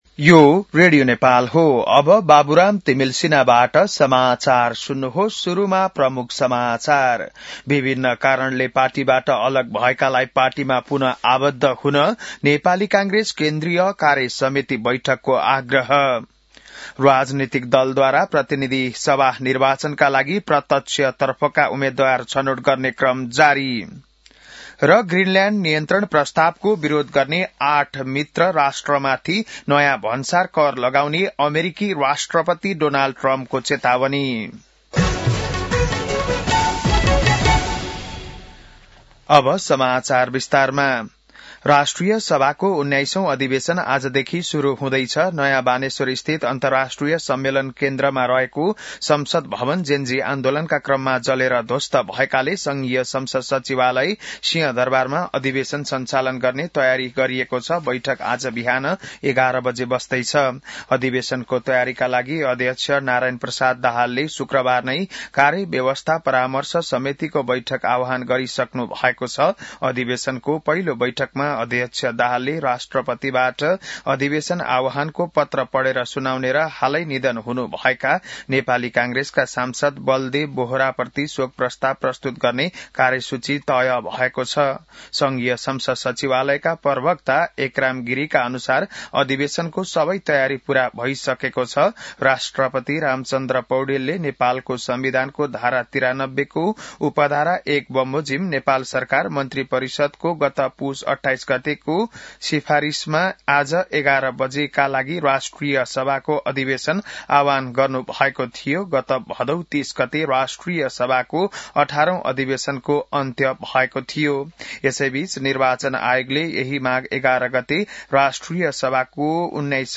बिहान ९ बजेको नेपाली समाचार : ४ माघ , २०८२